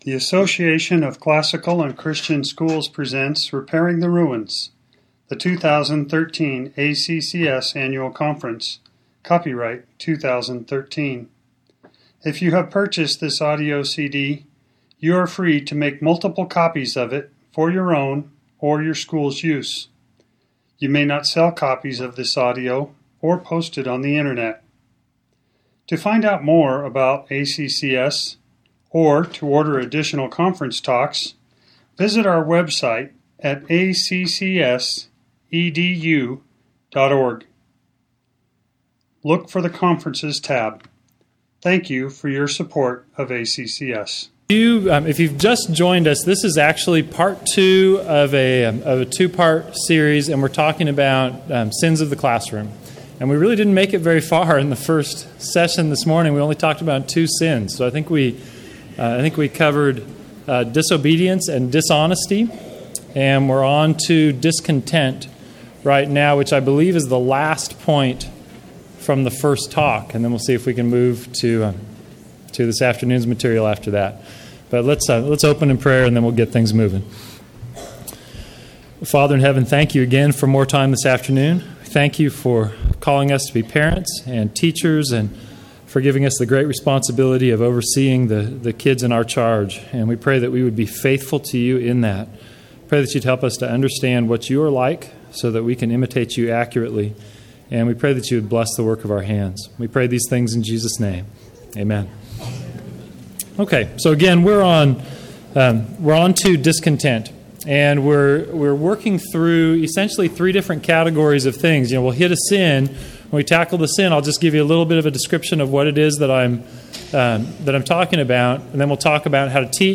2013 Workshop Talk | 1:03:13 | All Grade Levels, Virtue, Character, Discipline